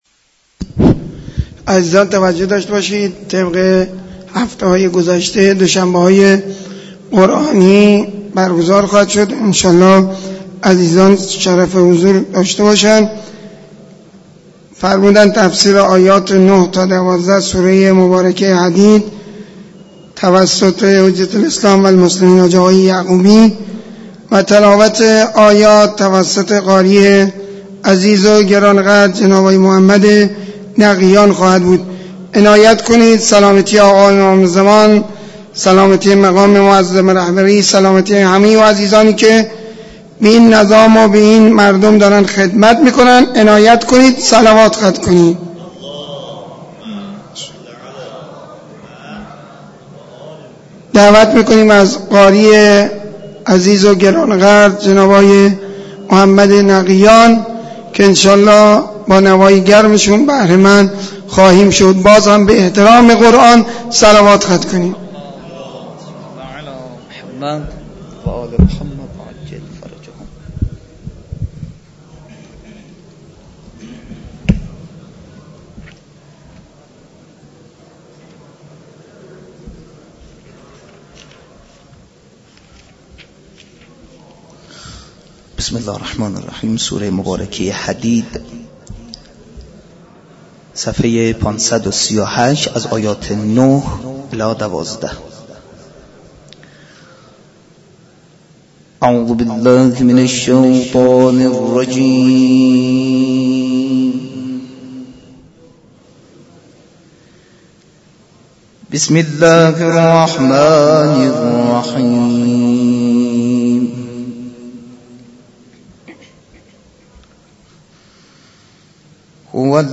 برگزاری مراسم معنوی دوشنبه های قرآنی در مسجد دانشگاه کاشان